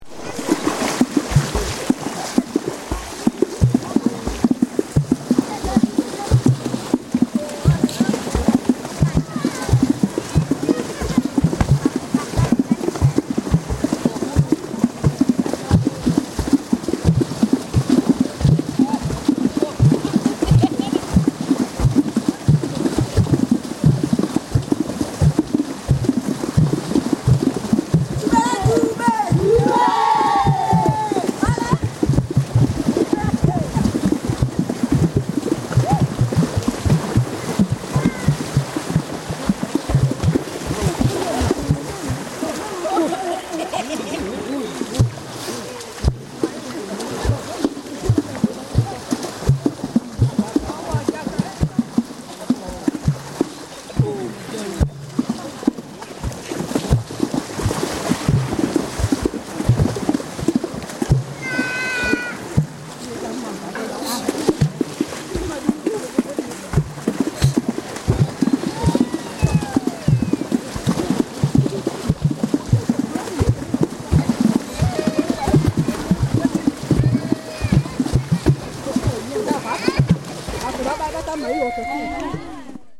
Bayaka group water drumming
Bayaka group water drumming, with rhythm changes.
From the sound collections of the Pitt Rivers Museum, University of Oxford, being from a large collection of cassette tape and digital audio tape recordings of Bayaka music and soundscapes